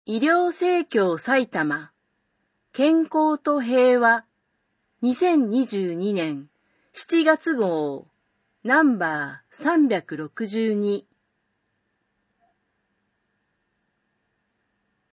2022年7月号（デイジー録音版）